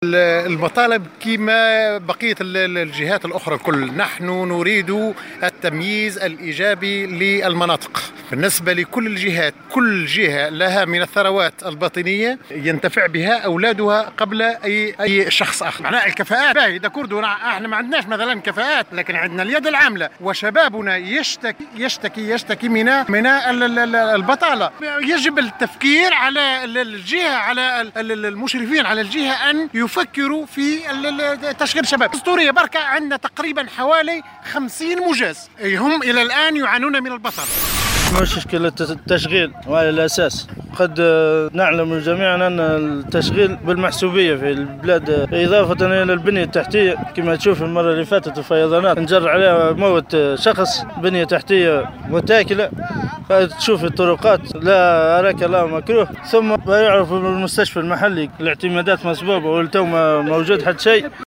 محتجون تحميل المشاركة علي